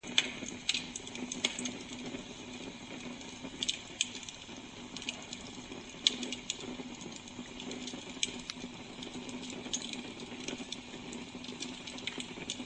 Fuoco del camino
fuoco-ok.mp3